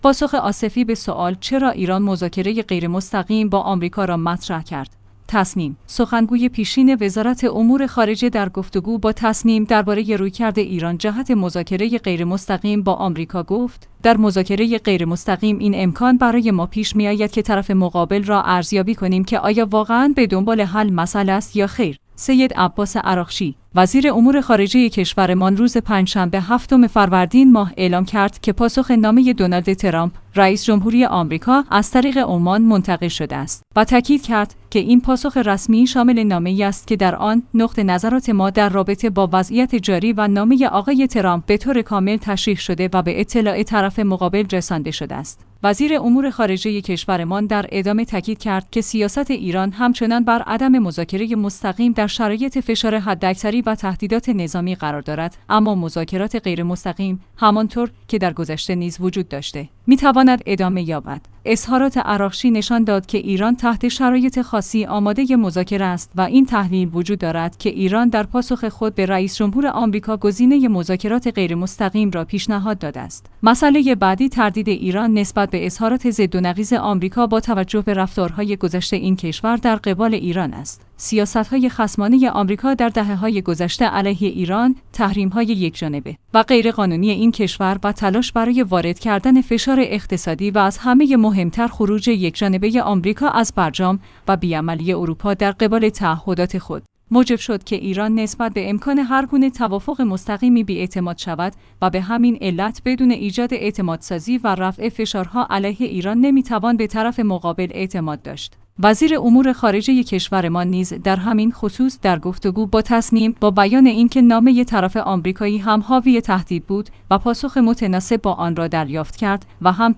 تسنیم/ سخنگوی پیشین وزارت امور خارجه در گفتگو با تسنیم درباره رویکرد ایران جهت مذاکره غیرمستقیم با آمریکا گفت: در مذاکره غیرمستقیم این امکان برای ما پیش می‌آید که طرف مقابل را ارزیابی کنیم که آیا واقعاً به دنبال حل مسئله است یا خیر.